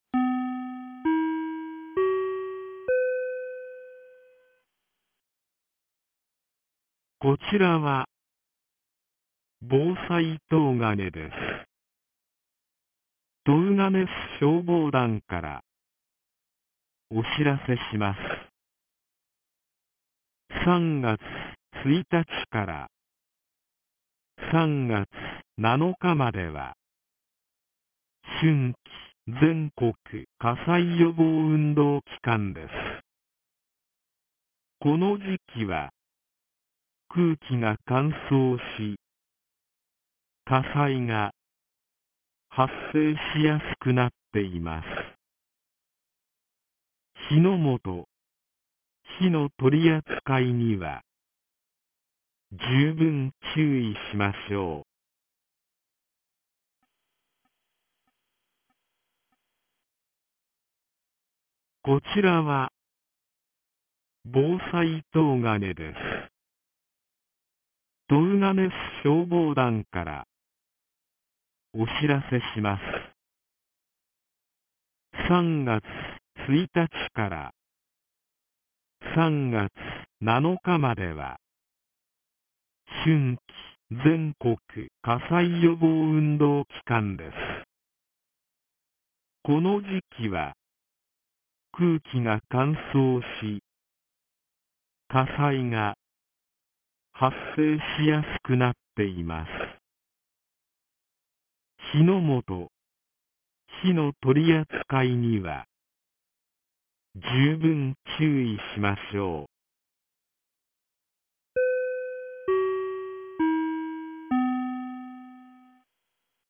2025年03月01日 09時31分に、東金市より防災行政無線の放送を行いました。